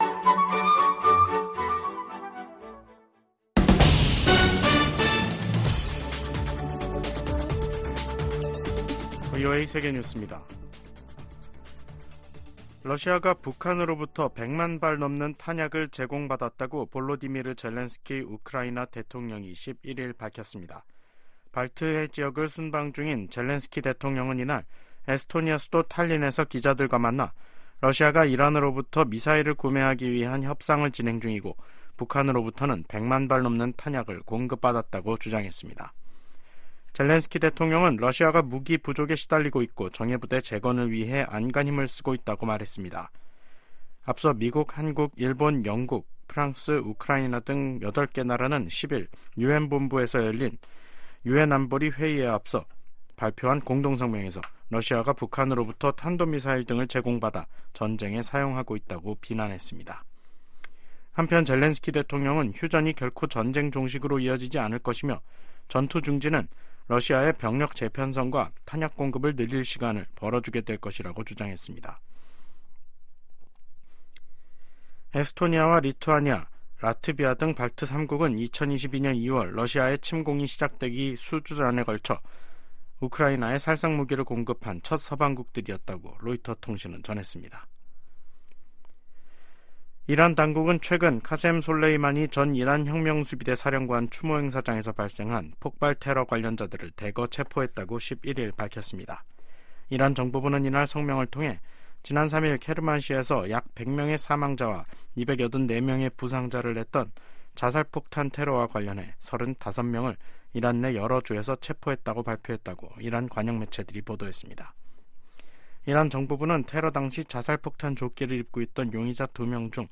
VOA 한국어 간판 뉴스 프로그램 '뉴스 투데이', 2024년 1월 11일 3부 방송입니다. 백악관은 팔레스타인 무장정파 하마스가 북한 무기를 사용한 사실을 인지하고 있다고 밝혔습니다. 미국, 한국, 일본 등이 유엔 안보리 회의에서 러시아가 북한에서 조달한 미사일로 우크라이나를 공격하고 있는 것을 강력하게 비판했습니다. 미국은 중국과의 올해 첫 국방 정책 회담에서 북한의 최근 도발에 우려를 표명하고 철통 같은 인도태평양 방위 공약을 재확인했습니다.